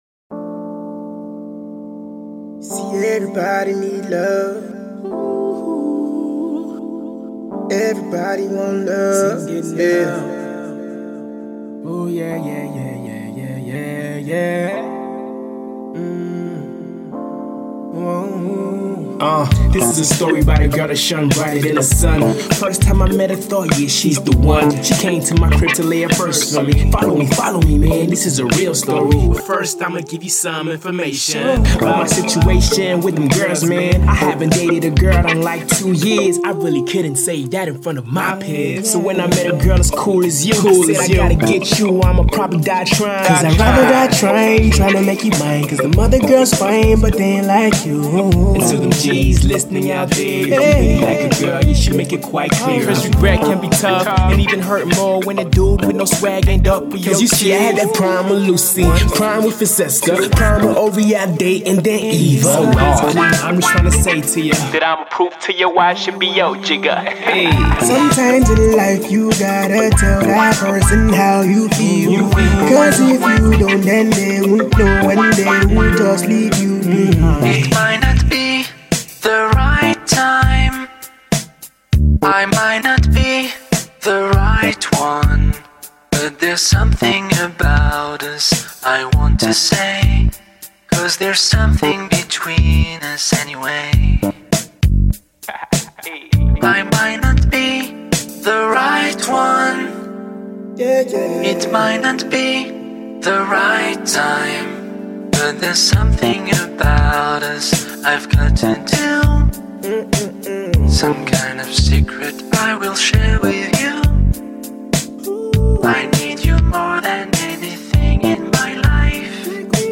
’90s rap flow